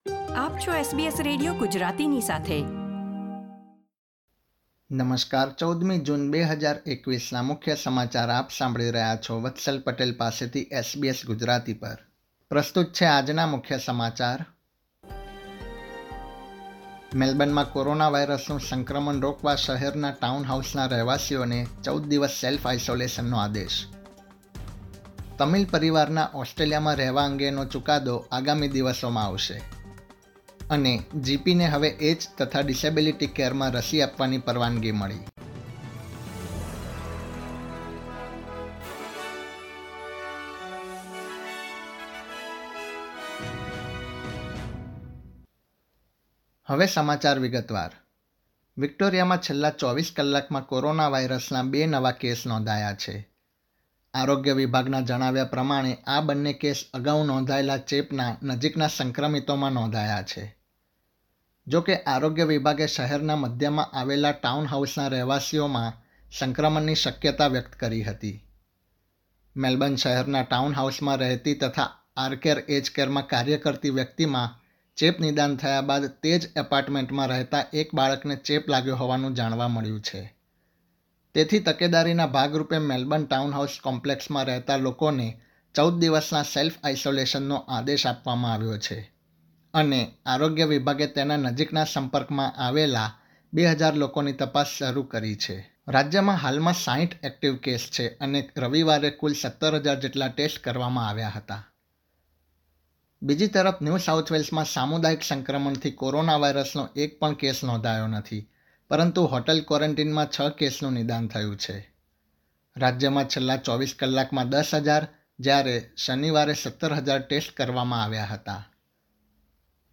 SBS Gujarati News Bulletin 14 June 2021